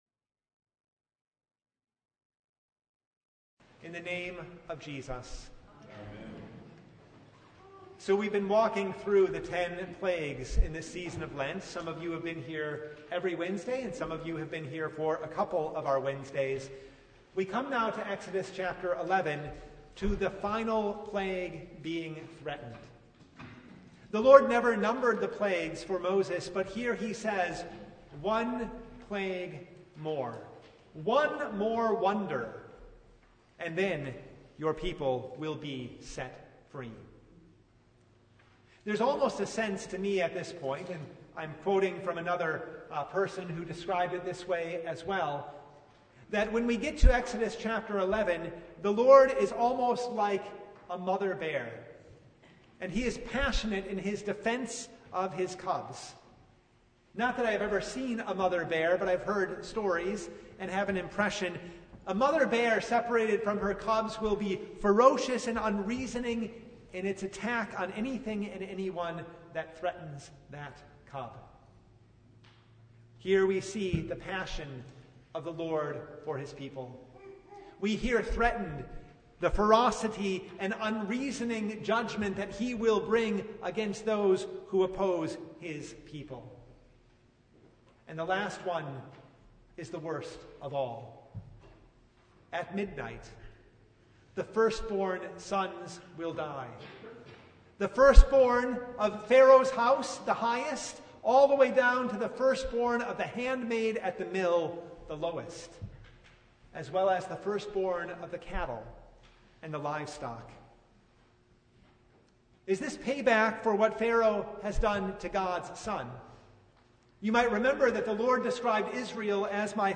Service Type: Lent Midweek Noon
Sermon Only